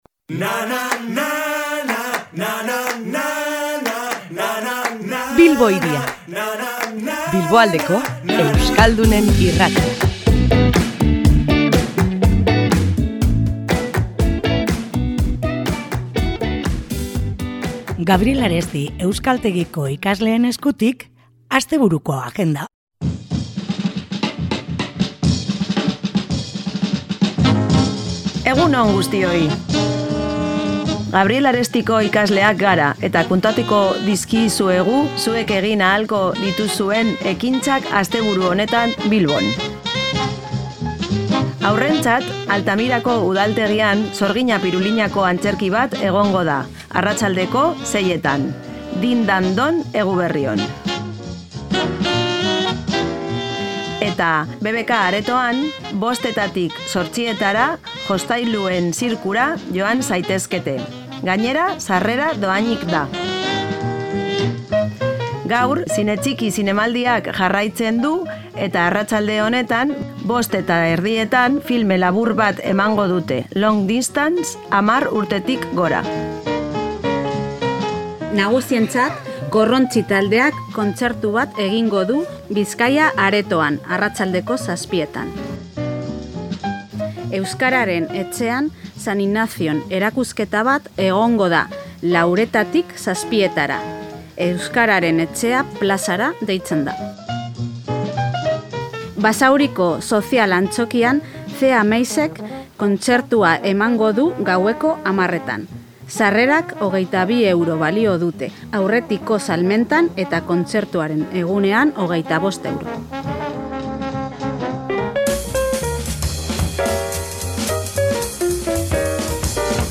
Gabriel Aresti euskaltegiko ikasleak izan ditugu gurean, eta astebururako planak ekarri dizkigute Bilbo Hiria irratira. Gabonetako programa ekarri digute, izan ere hainbat emanaldi berezi daude Bilbon.